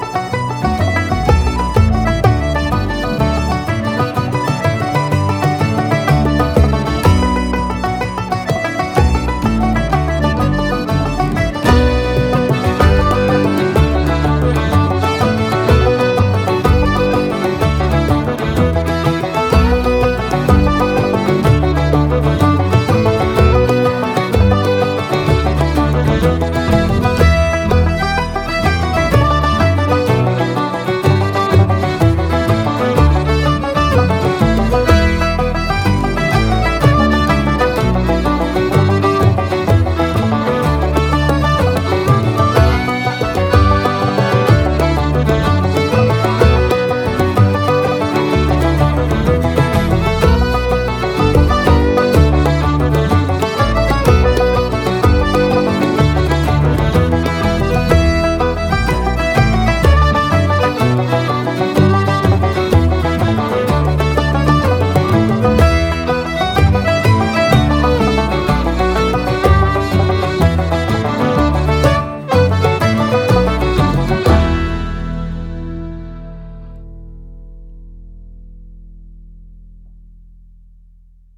Double Bass
recorded in Bann View studios, Co. Antrim
piano accordion adds a reviving Appalachian tang